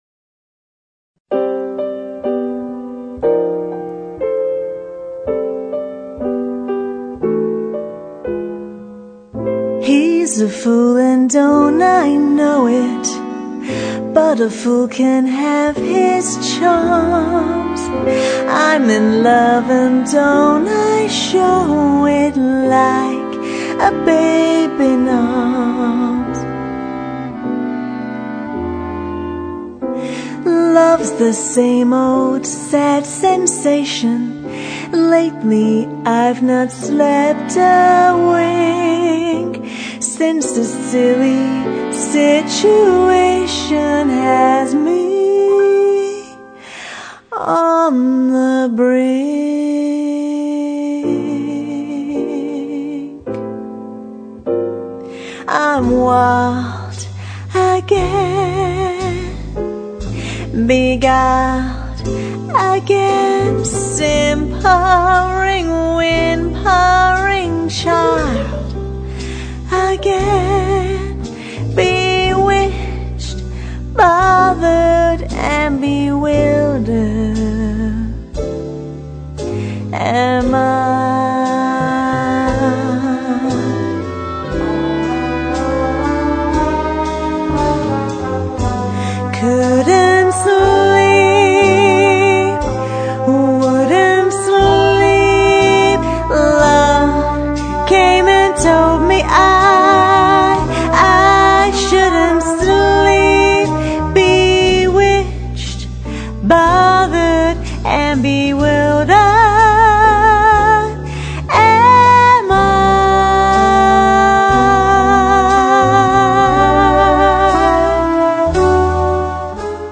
• Six-piece band
• Two female lead vocalists
Jazz